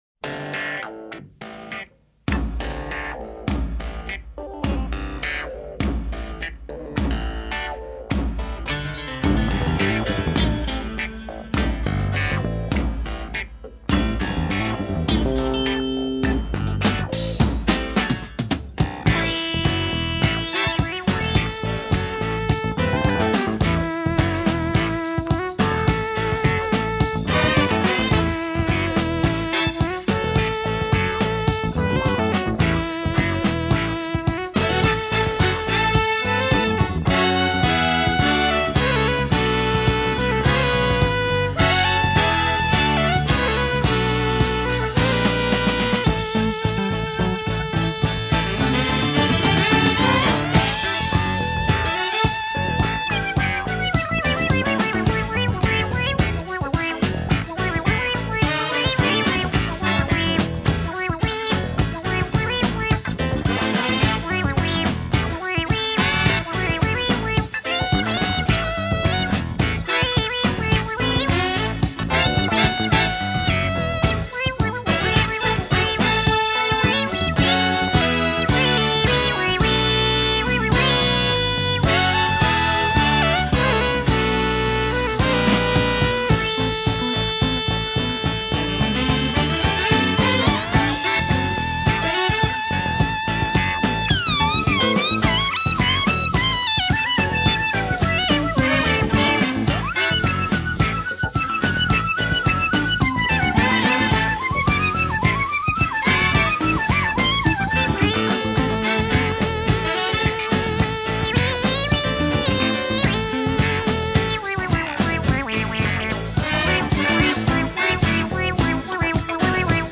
kitschy space-funk instrumentals
the one that could be a Taxi-like TV show theme.